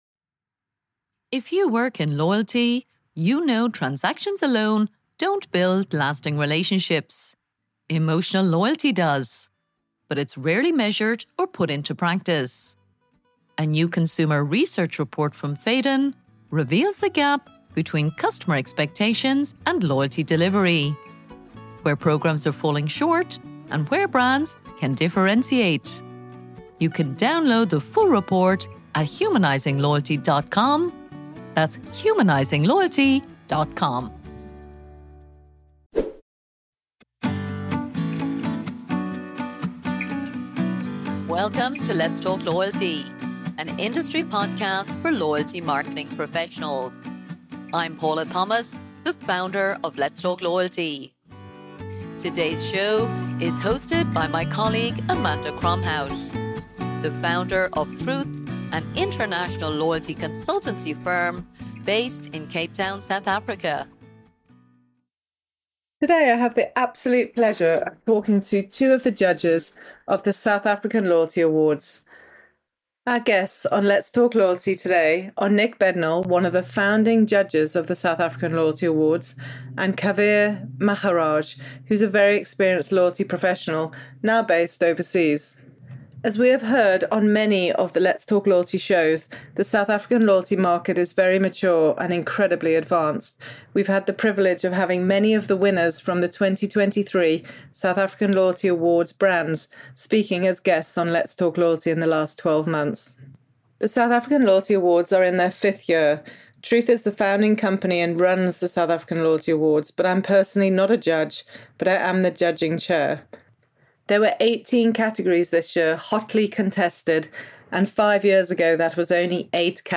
The Let’s Talk Loyalty Show interviews two of the judges who had the challenging job of judging the high calibre entries across 18 different competitive categories.